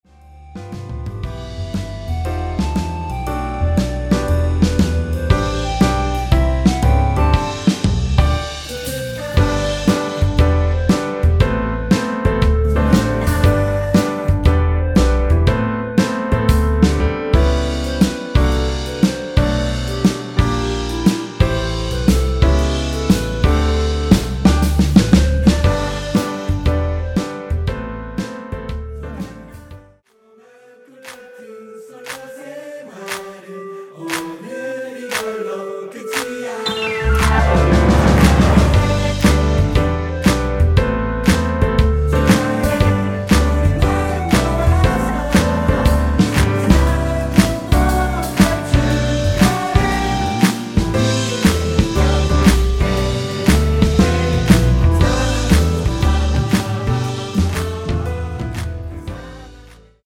원키 멜로디와 코러스 포함된 MR입니다.(미리듣기 참조)
앞부분30초, 뒷부분30초씩 편집해서 올려 드리고 있습니다.
중간에 음이 끈어지고 다시 나오는 이유는